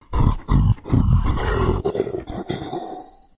生物的声音 " Creture Curiosity ?嗅觉 11/14
Tag: 怪物 恐怖 僵尸